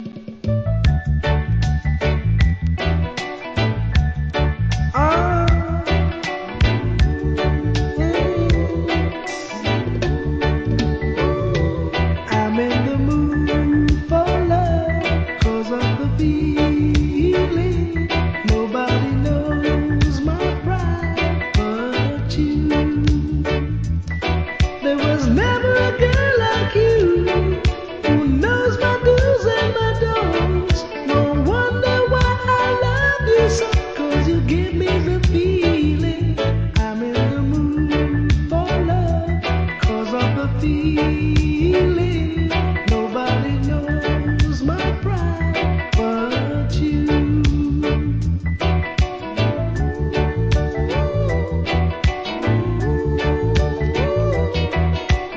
REGGAE
素晴らしいSWEETヴォーカルを堪能できるLOVERS決定盤♪